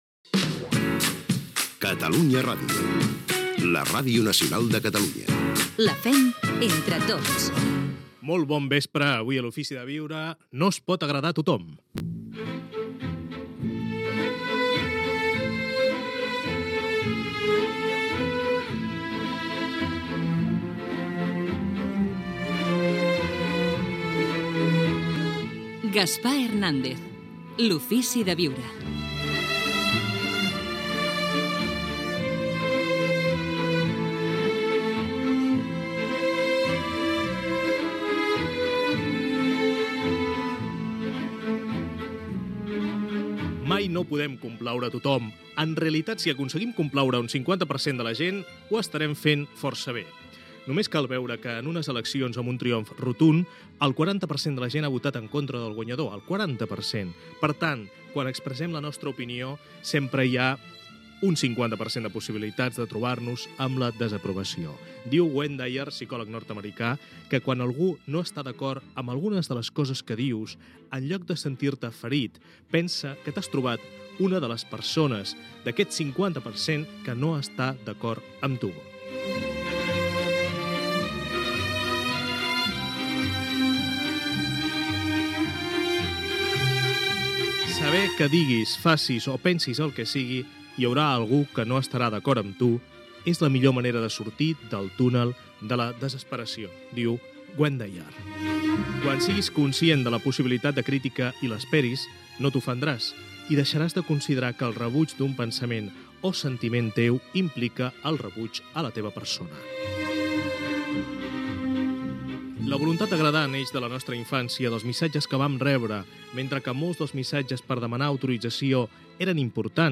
Indicatiu de l'emissora i del programa. Presentació de l'espa dedicat al tema "No es pot agradar a tothom"